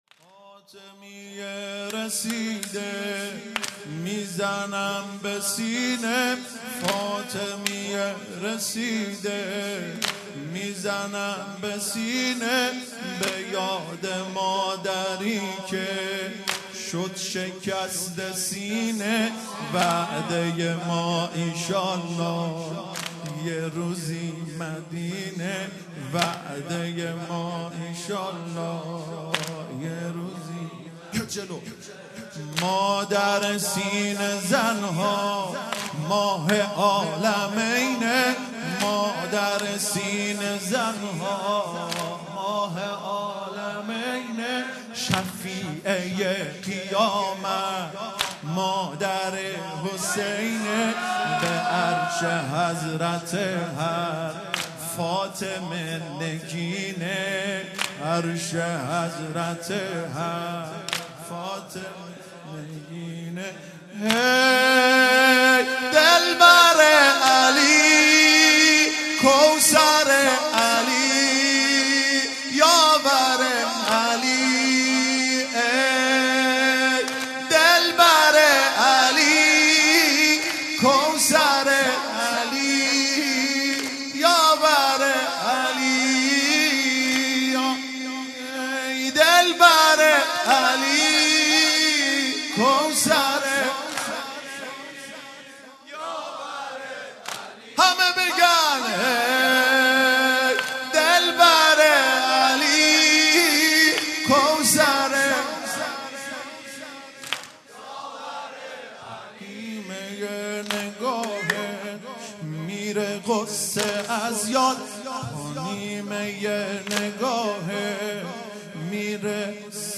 هیئت دانشجویی فاطمیون دانشگاه یزد
فاطمیه رسیده|شهادت حضرت زهرا (س) ۲۵ بهمن ۹۵